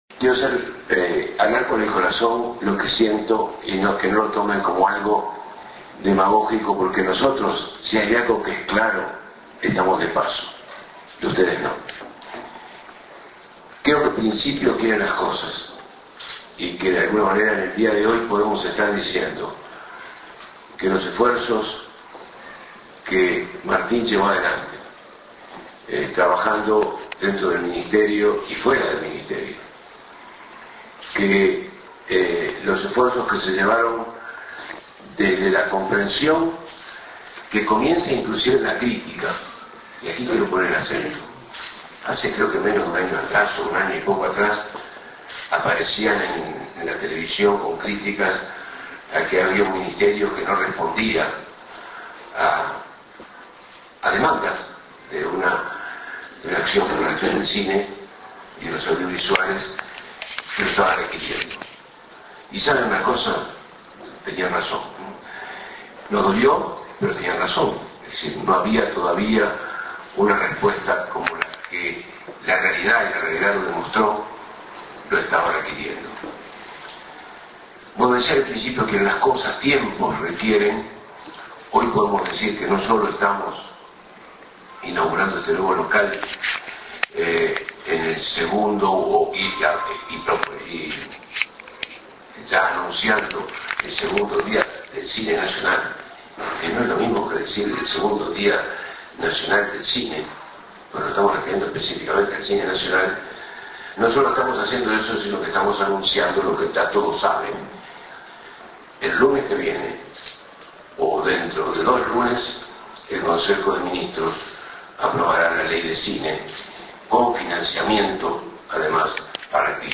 Palabras del Ministro de Educación y Cultura, Jorge Brovetto, en el acto de lanzamiento del Día Nacional del Cine.